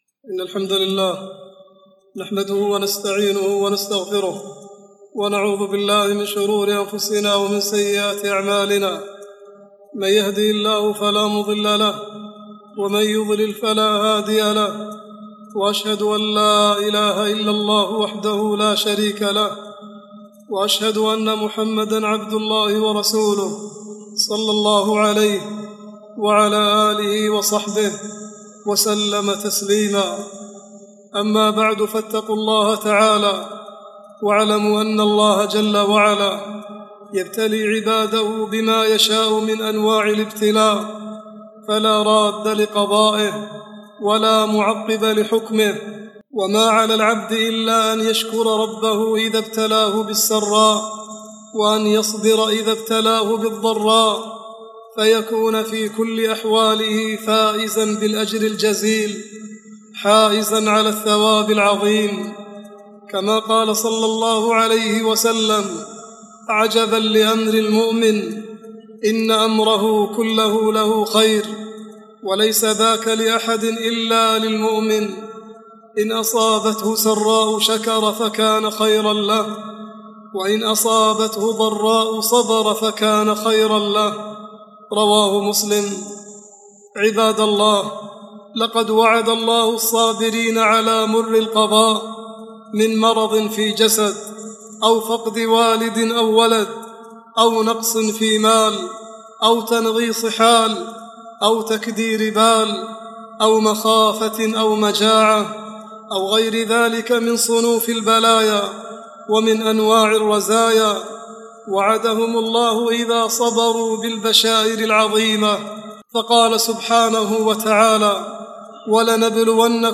khutbah-9-8-38.mp3